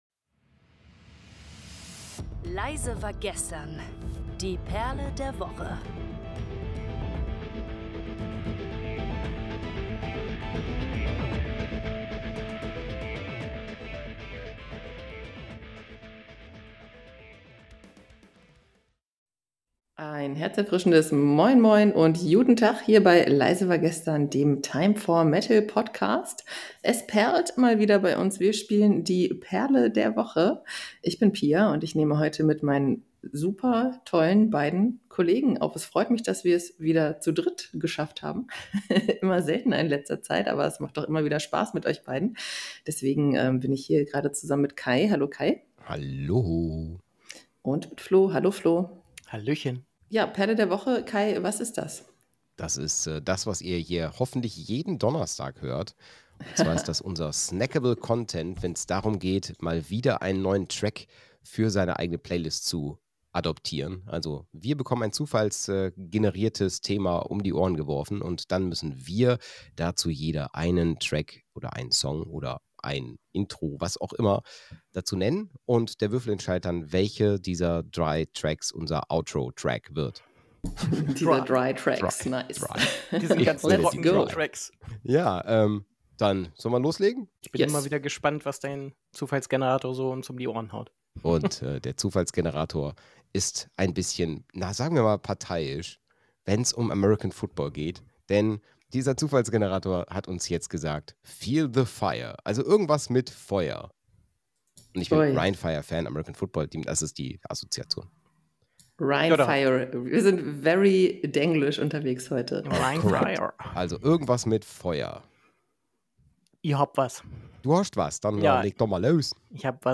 In dieser Episode des Time for Metal Podcasts spielen die Moderatoren die Perle der Woche. Sie wählen Songs zum Thema Feuer und diskutieren ihre Assoziationen dazu. Es werden Tracks von Hypocrisy, Dragonforce und Killswitch Engage vorgestellt.